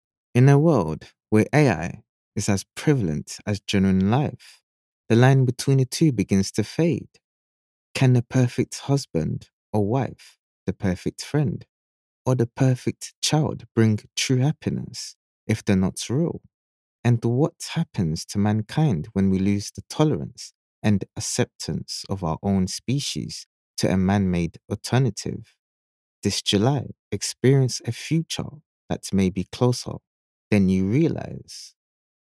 English (Caribbean)
Yng Adult (18-29) | Adult (30-50)